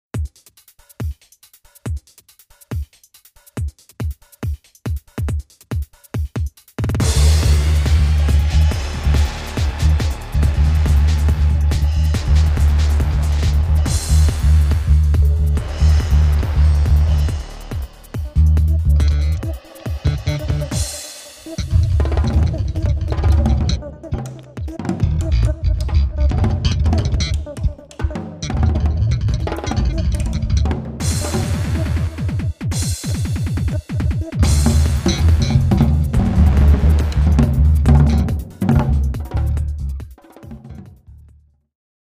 Australian, Classical